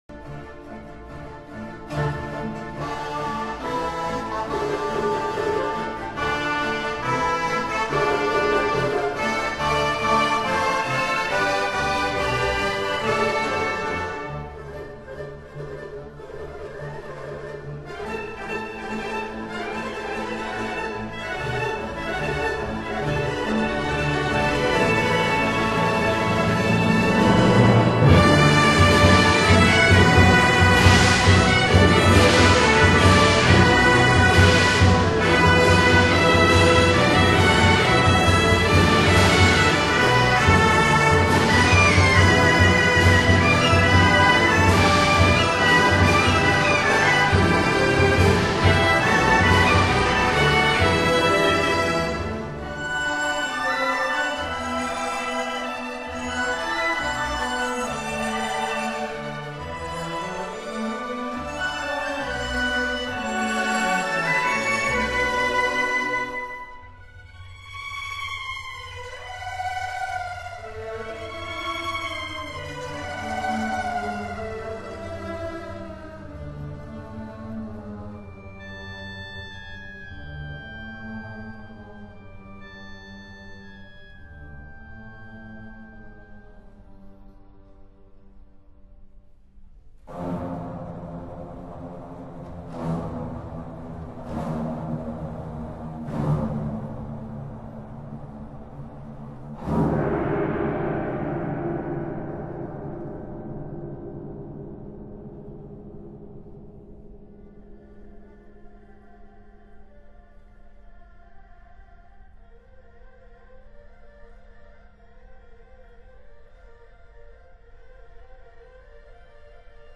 幻想曲